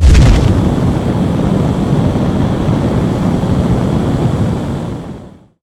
attack_hit_flame.ogg